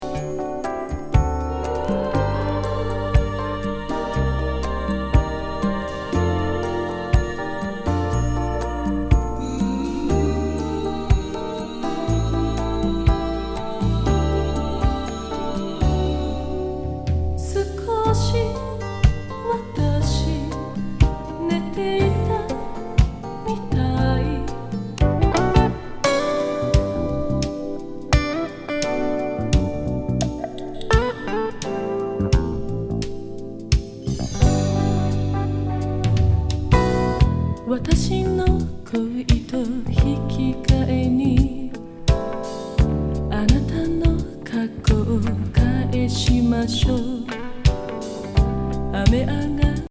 アンニュイ・メロウ